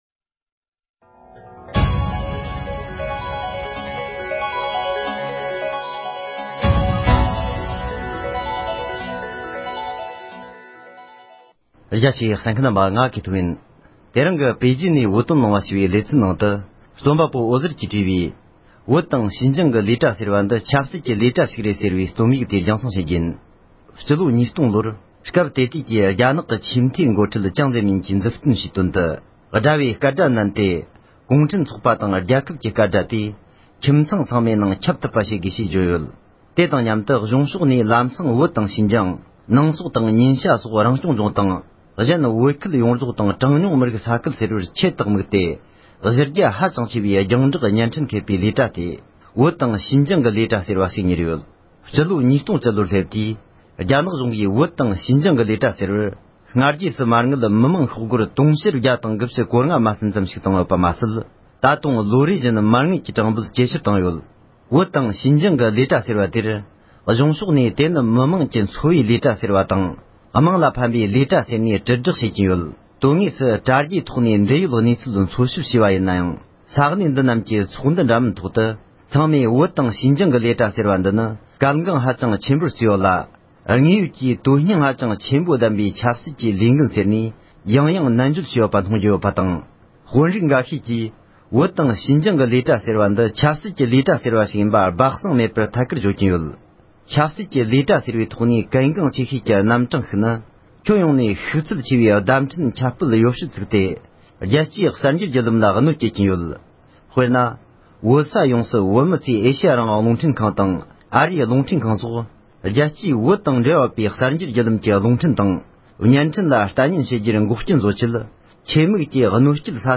༄༅༎དེ་རིང་གི་པེ་ཅིང་ནས་བོད་དོན་གླེང་བ་ཞེས་པའི་ལེ་ཚན་ནང་དུ། རྩོམ་པ་པོ་འོད་ཟེར་ལགས་ཀྱིས་བྲིས་པའི་བོད་དང་ཞིན་ཅང་གི་ལས་གྲྭ་ཟེར་བ་དེ་ནི། ཆབ་སྲིད་ཀྱི་ལས་གྲྭ་ཞིག་རེད་ཅེས་པའི་རྩོམ་ཡིག་དེ། ཕབ་བསྒྱུར་དང་སྙན་སྒྲོན་ཞུས་པར་གསན་རོགས་ཞུ༎